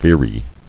[vee·ry]